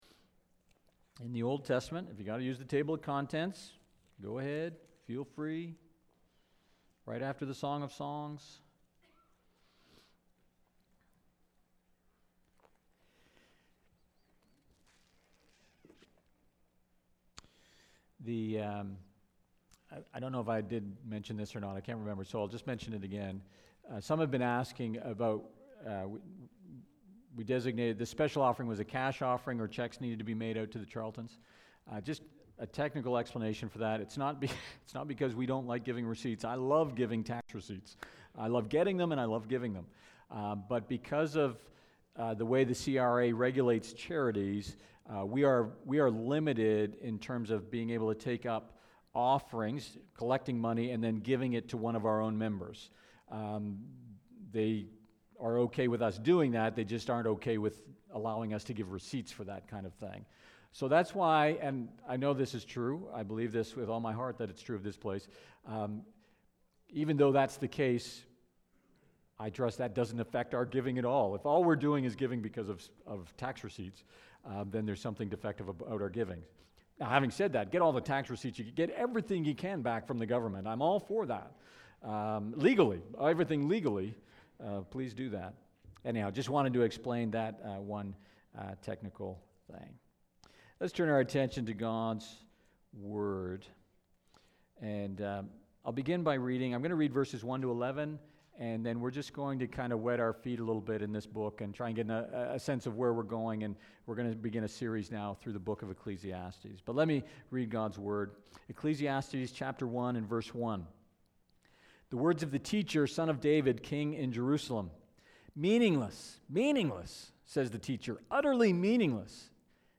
Sermons | Port Perry Baptist